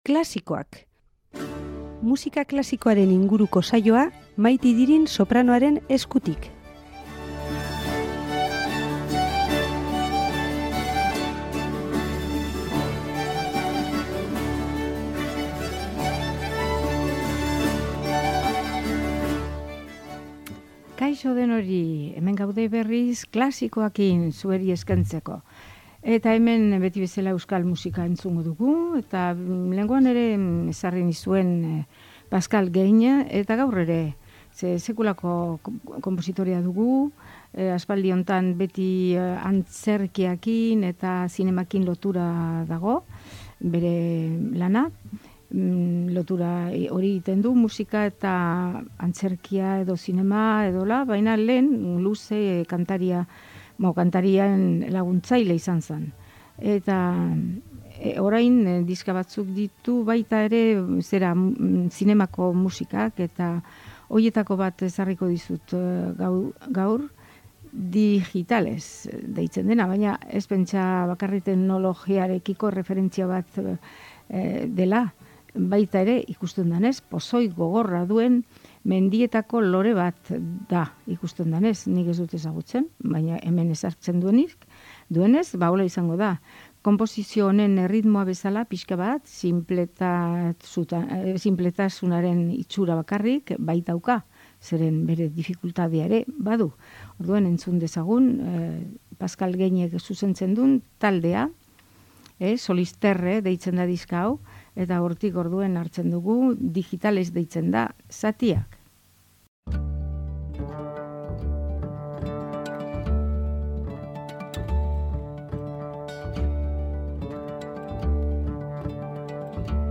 musika klasikoaren saioa